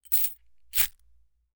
Metal_39.wav